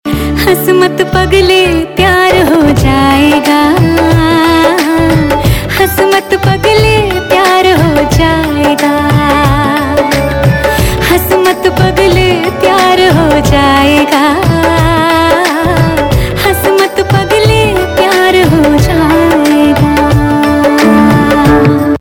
CategoryHindi Ringtones